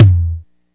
DRUMULATOR_LT.wav